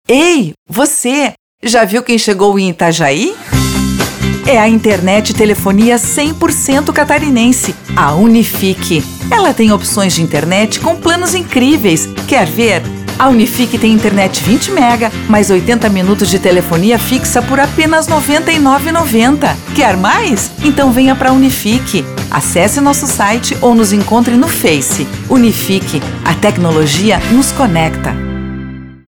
Feminino
Voz Jovem 00:29